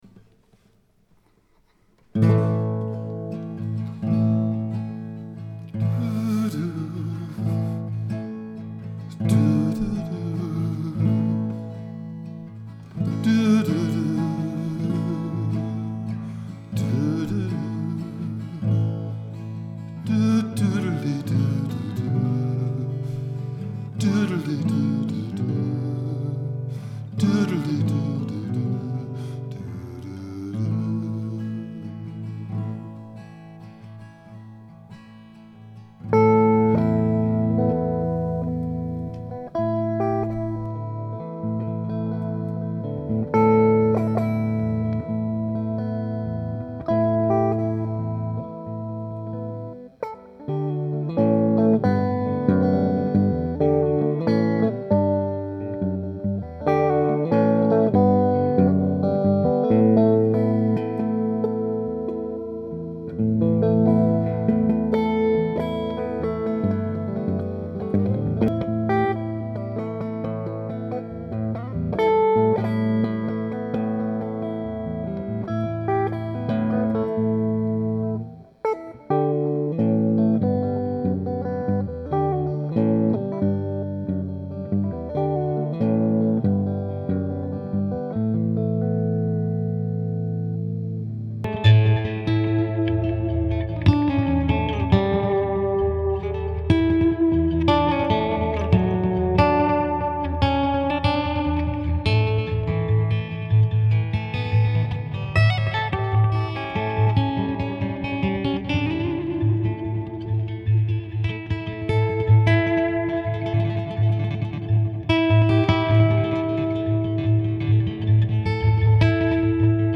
Simple voice and guitar with excerpts from Opus 54